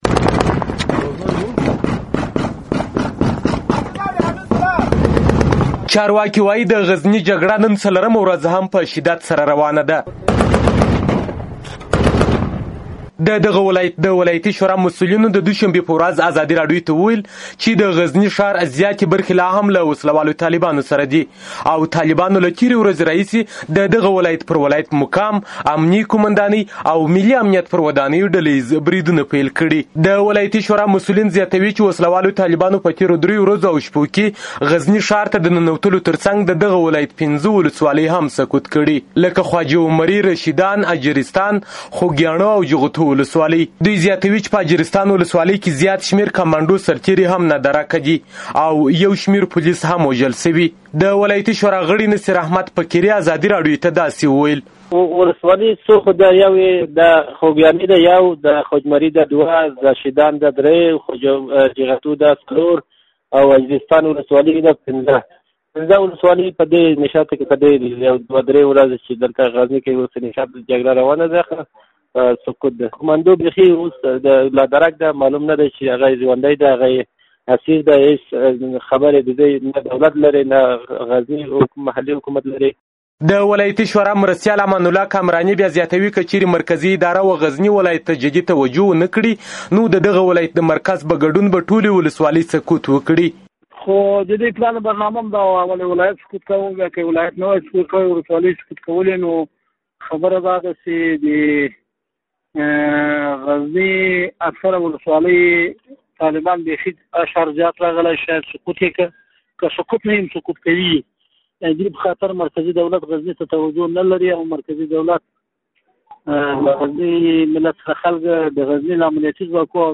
د غزني راپور